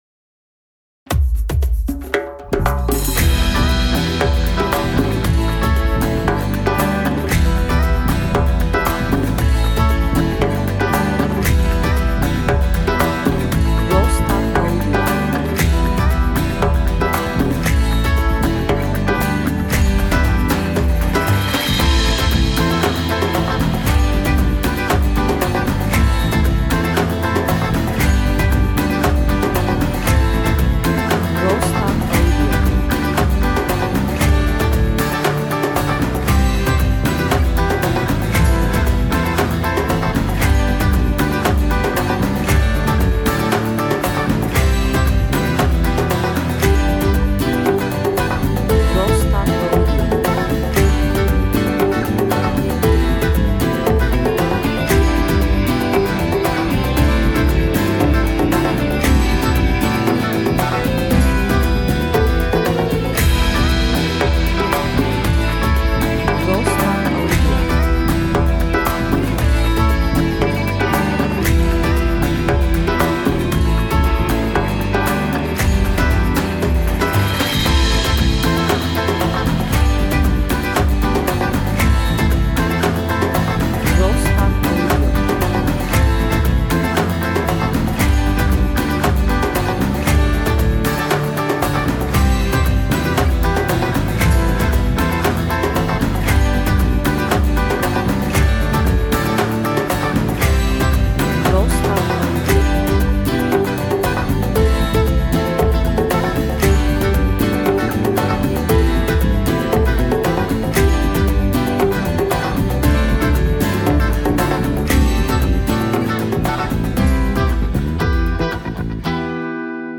enstrümantal hareketli müzik